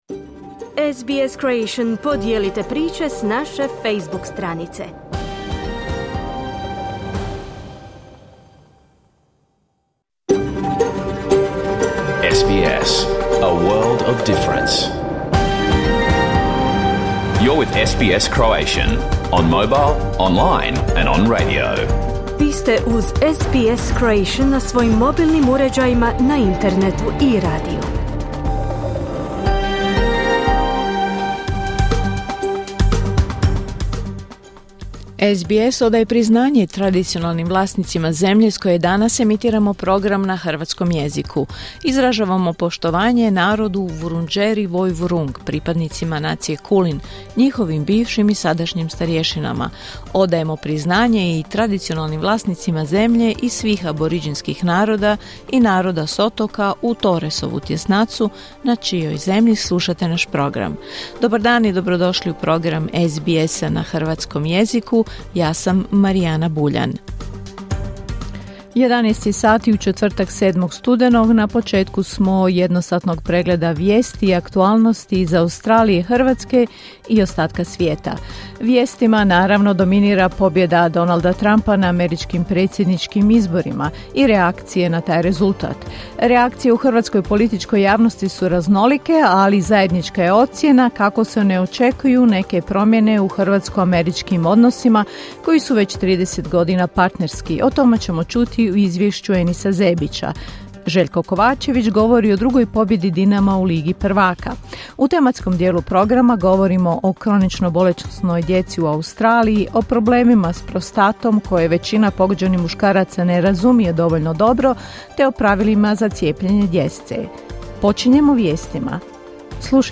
Vijesti i aktualne teme iz Australije, Hrvatske i svijeta. Emitirano uživo na radiju SBS1 u četvrtak, 7. studenog u 11 sati po istočnoaustralskom vremenu.